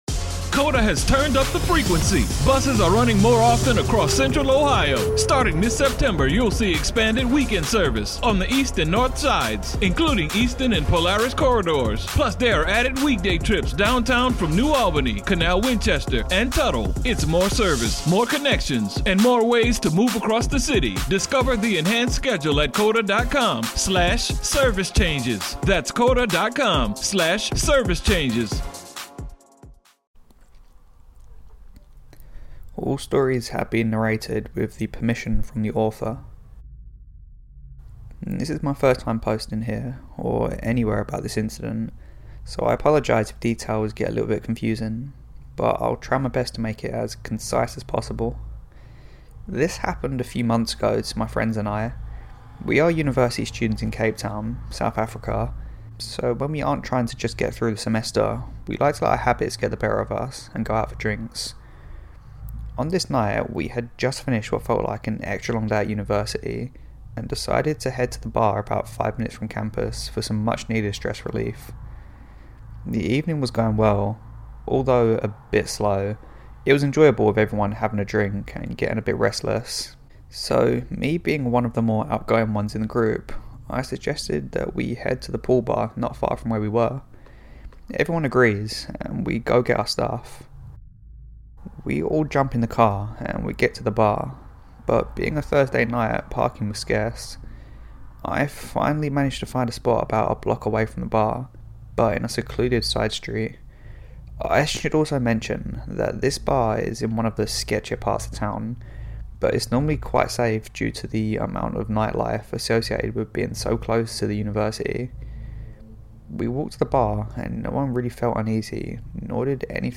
Hi everyone, here are some new instalments of true scary stories and also a nice chat at the end as usual.
Stories narrated with permission: